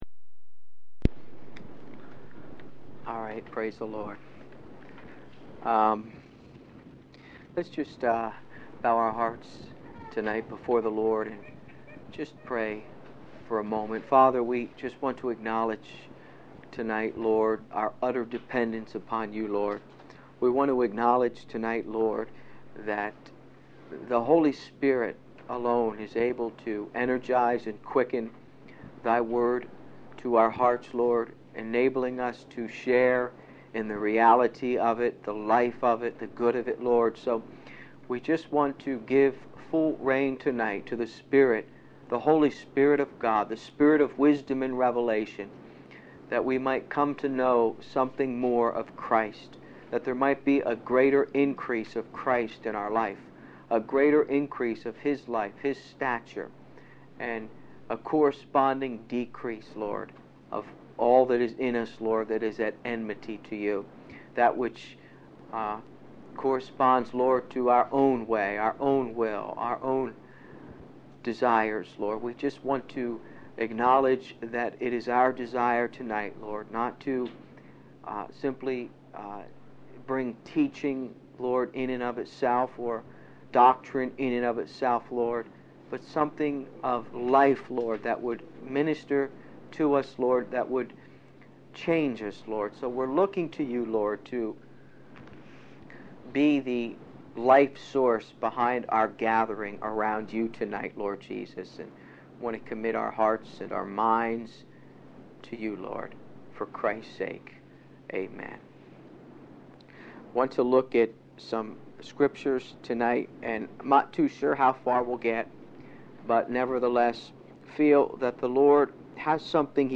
The sermon calls for a shift from a man-centered approach to a Christ-centered one, where believers acknowledge their inability to live the Christian life without the empowering presence of the Holy Spirit.